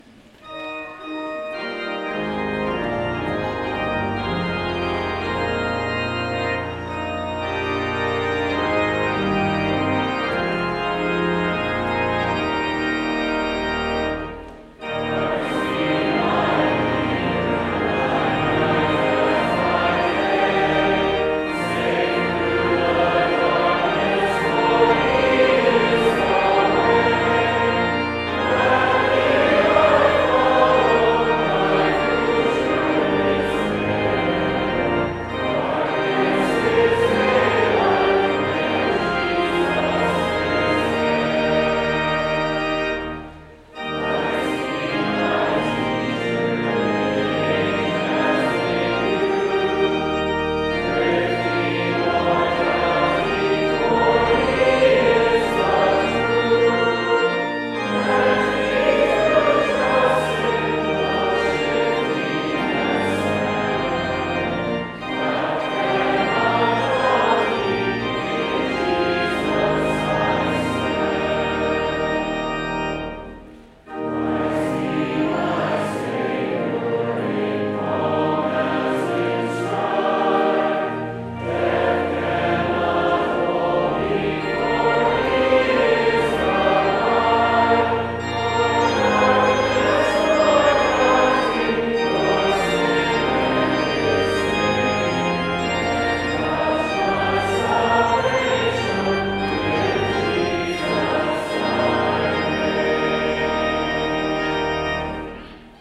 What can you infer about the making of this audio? music from 11:00 service to follow later today